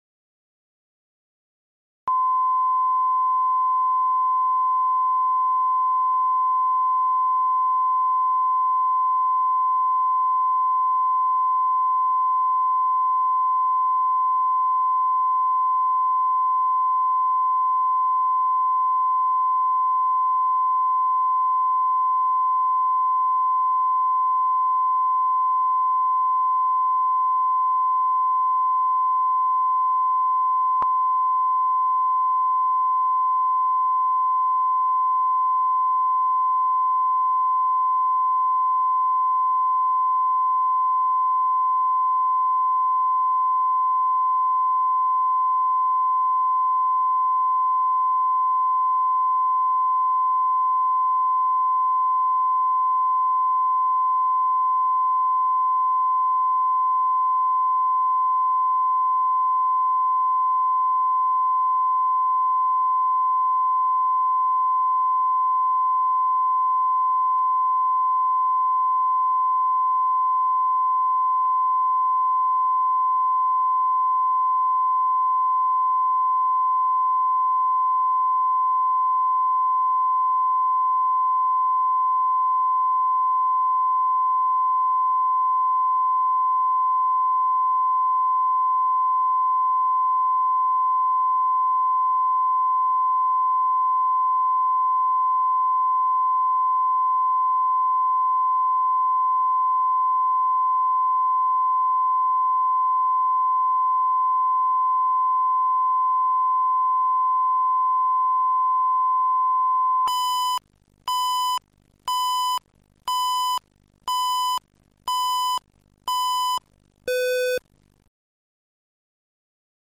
Аудиокнига Предложение | Библиотека аудиокниг